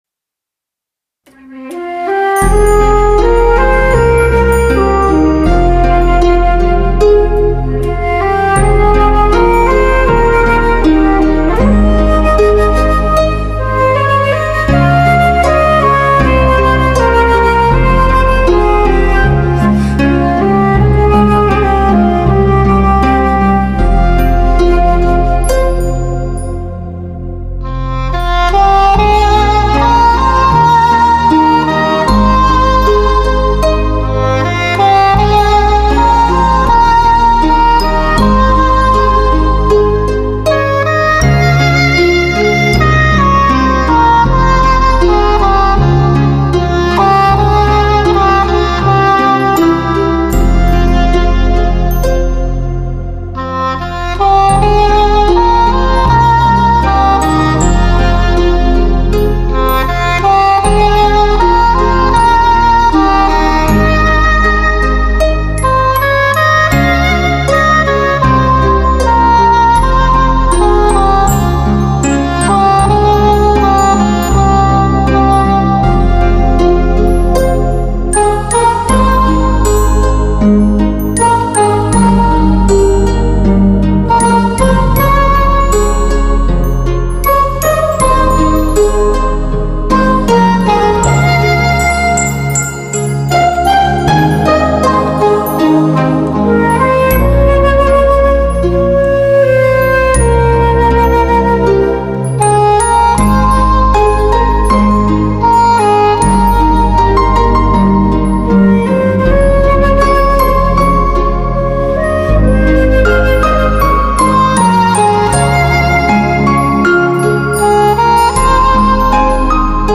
全方位多位环绕
发烧老情歌 纯音乐